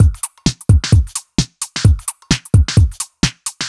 Index of /musicradar/uk-garage-samples/130bpm Lines n Loops/Beats
GA_BeatnPercE130-04.wav